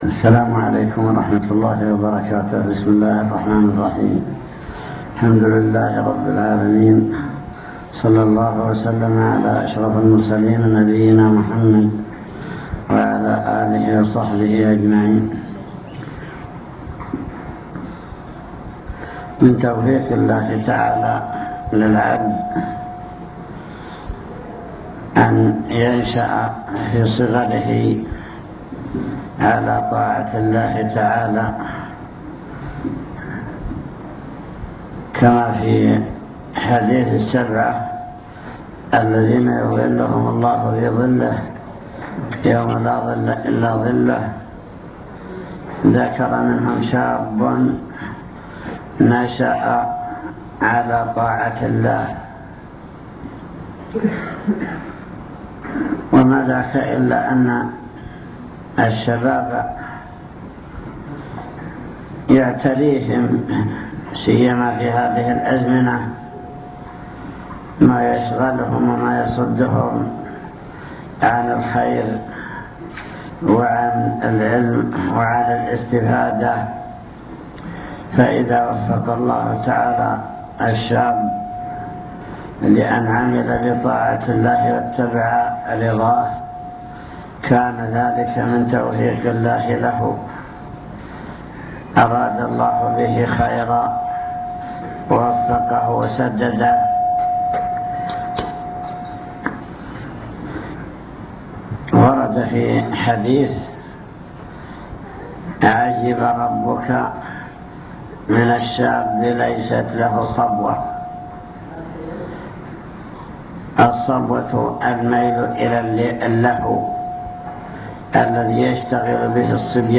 المكتبة الصوتية  تسجيلات - لقاءات  لقاء جماعة تحفيظ القرآن بالحريق